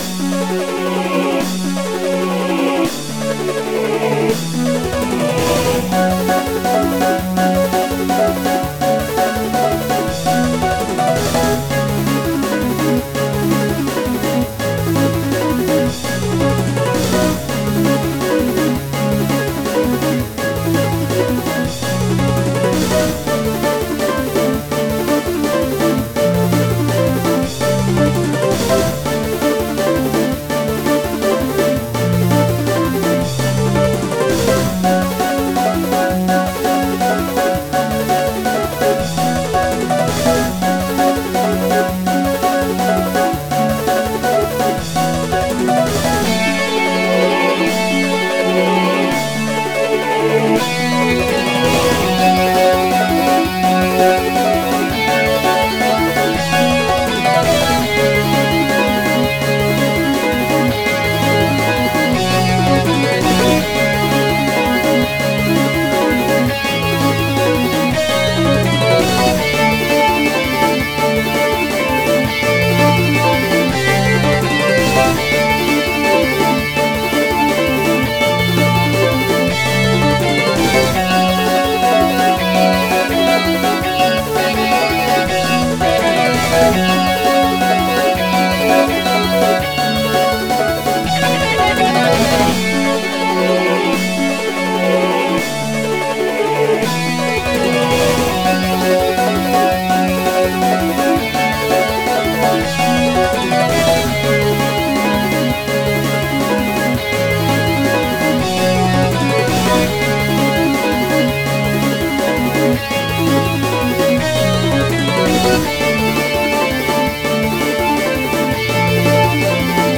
原游戏MIDI版，由Roland VSC-55导出。